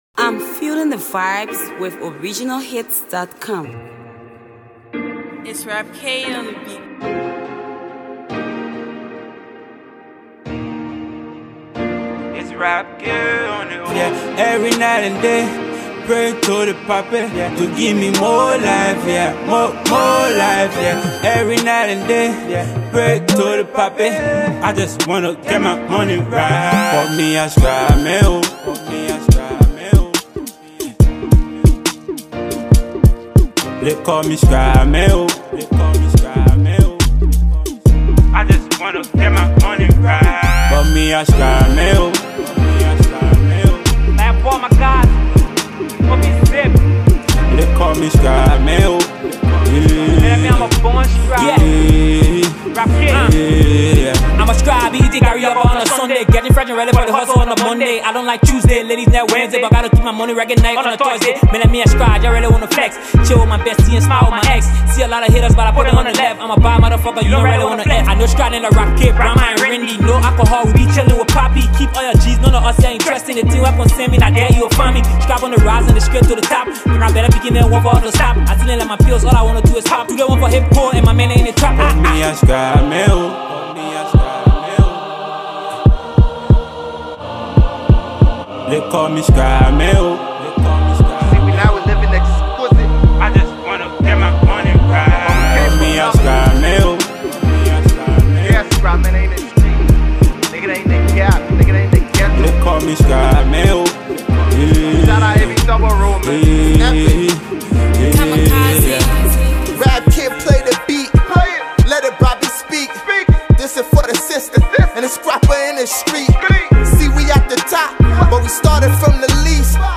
Afro Pop Hipco